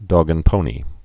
(dôgən-pōnē, dŏg-)